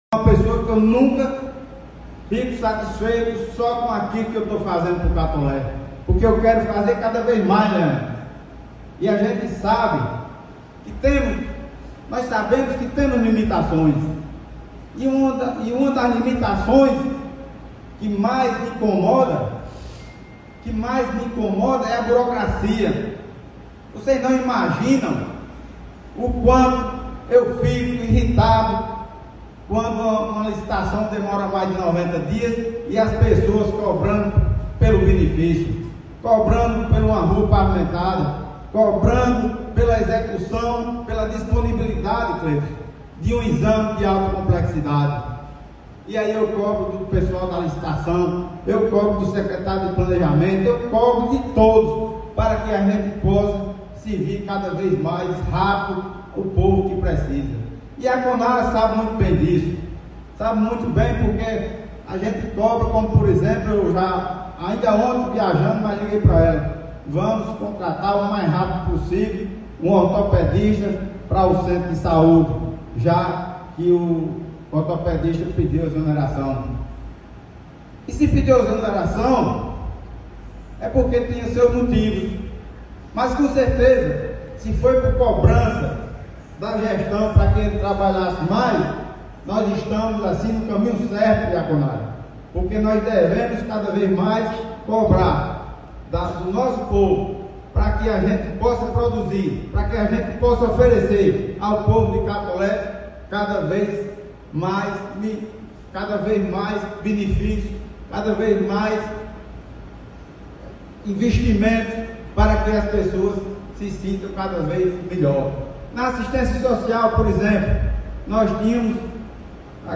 Prefeito Laurinho Maia participa da abertura da 4ª Conferência Municipal da Cidade em Catolé do Rocha.OUÇA!
Durante a abertura oficial, o prefeito Laurinho Maia fez um pronunciamento marcado pela franqueza e pelo compromisso com a população.
Acompanhe com atenção um trecho da sua fala:
03-PREFEITO-LAURINHO-MAIA-4a-CONFERENCIA-MUNICIPAL-DA-CIDADE-de-Catole-do-Rocha.mp3